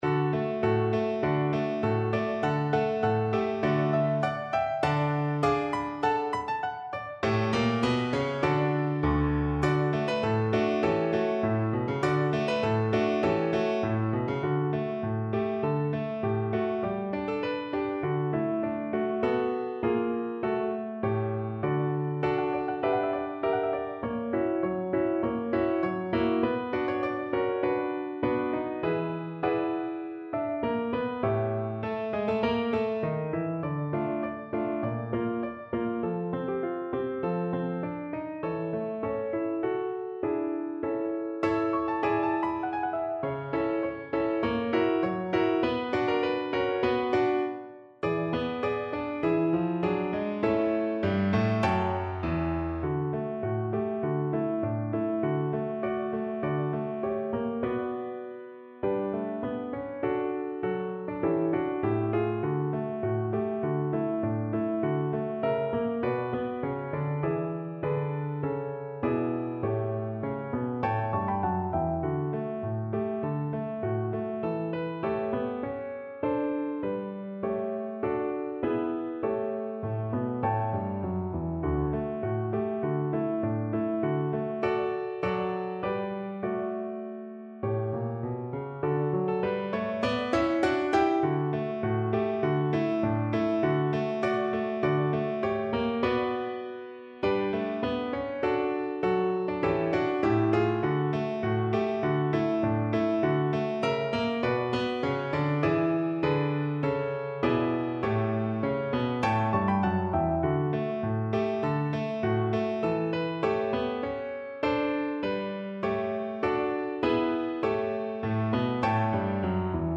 Tempo di Marcia
2/4 (View more 2/4 Music)